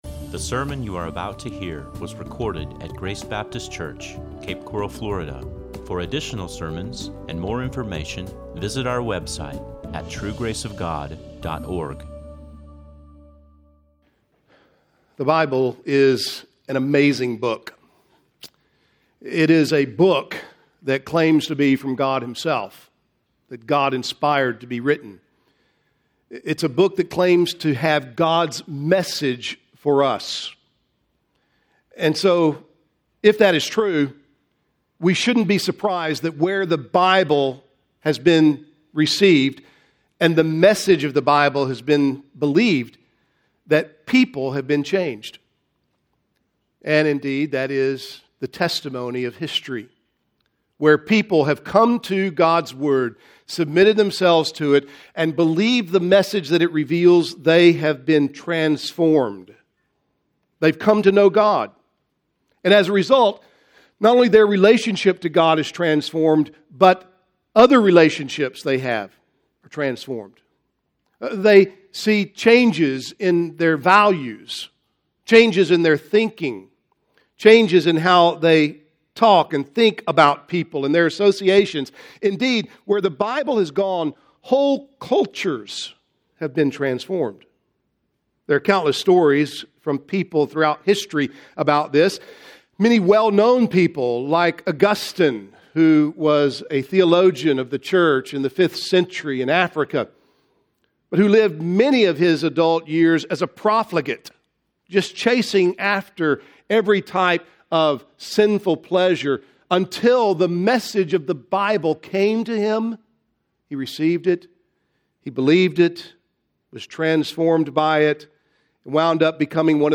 This is a sermon on Philemon 1:1-25.